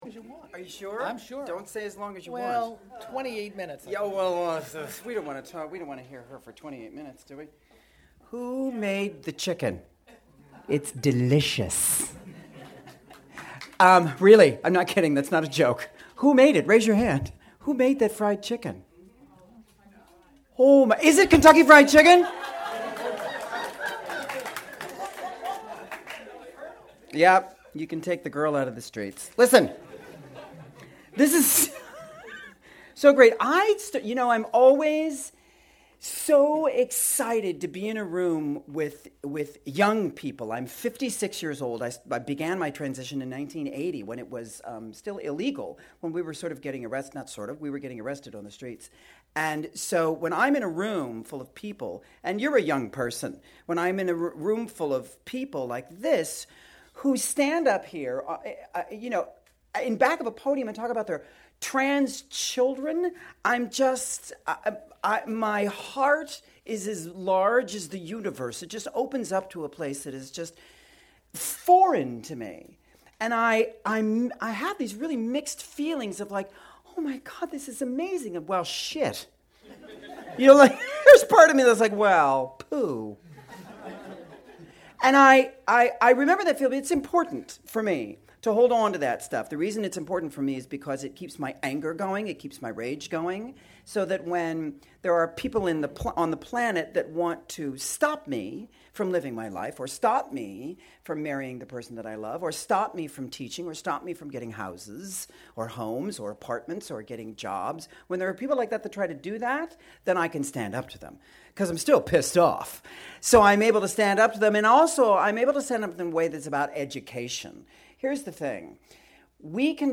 Transformation/TGEA/2nd Friday – Holiday Party on Sat Dec 15th
You are cordially invited to attend, enjoy some wonderful potluck, and to hear inspirational messages from this year’s awardees. click to download her speech Image courtesy of Alexandra Billings